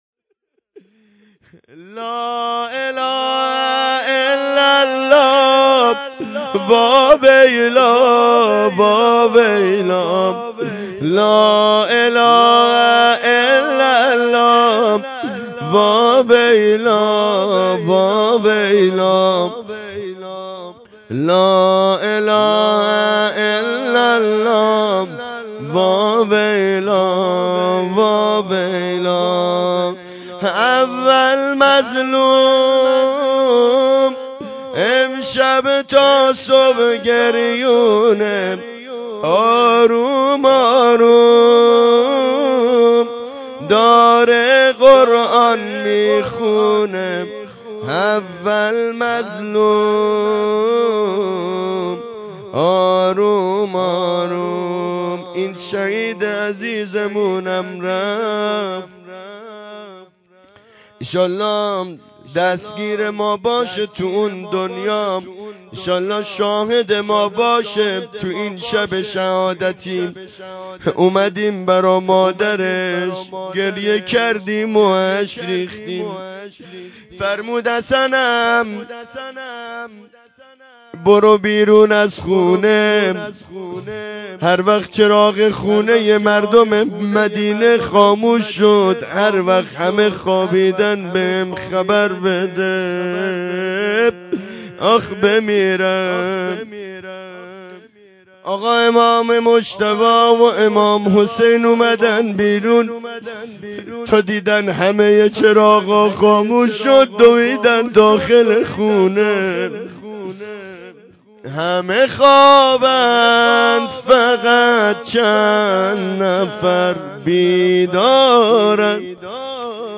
فاطمیه 1403 روایت 95 روز
روضه غسل و کفن روضه مادر